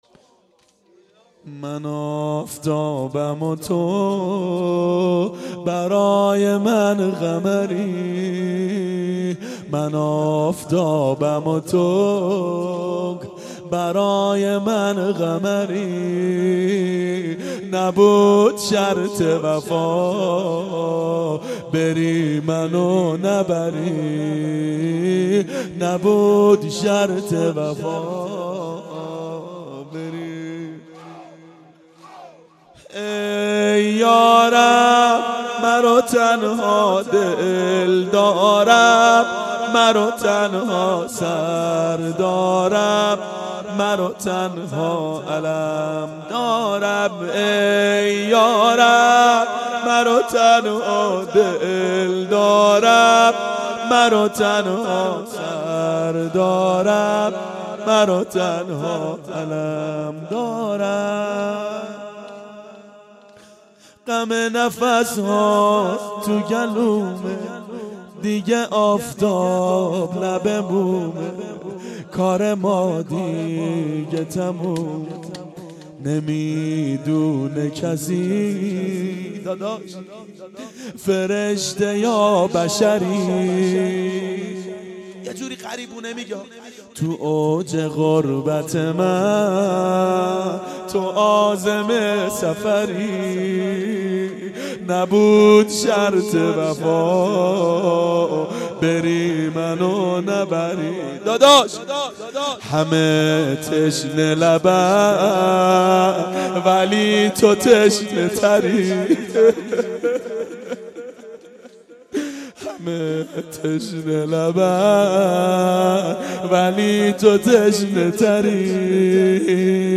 شب تاسوعا 92 هیأت عاشقان اباالفضل علیه السلام منارجنبان